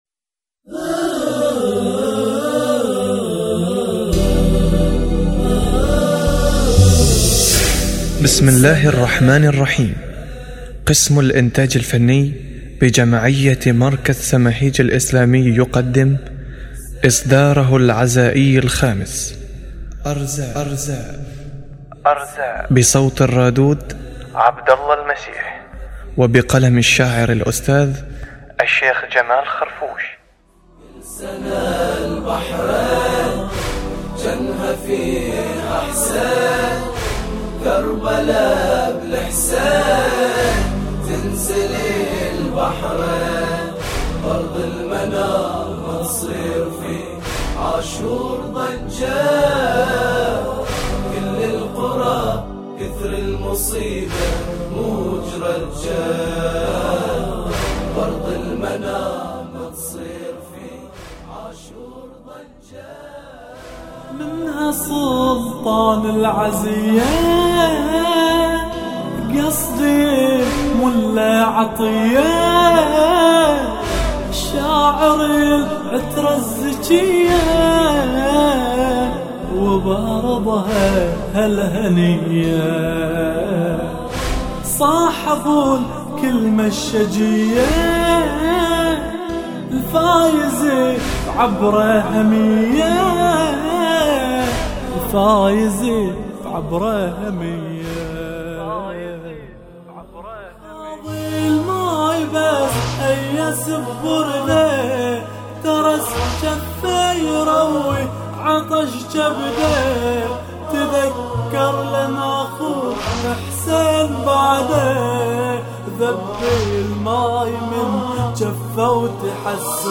إصداره العزائي الخامس
بصوت الرادود
تم التسجيل والمكساج في استوديو: